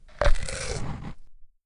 dino-eat.wav